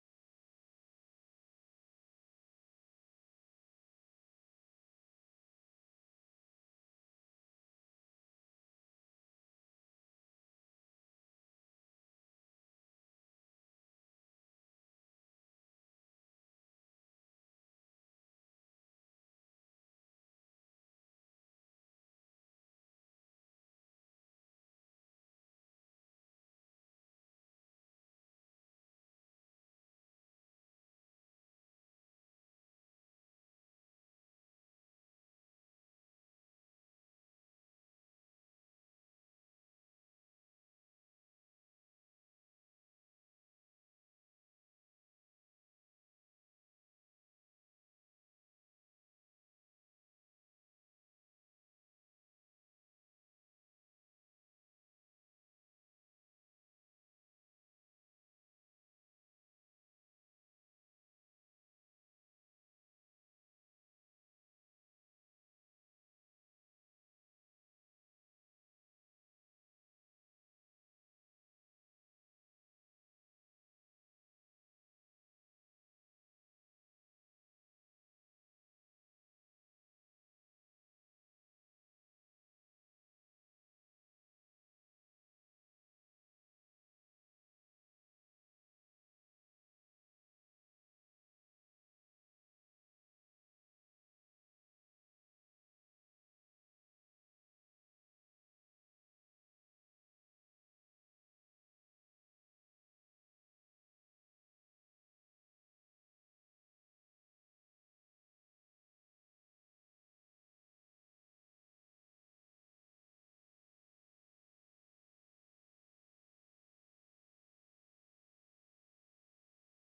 Oakwood Community Church Message Podcast | Oakwood Community Church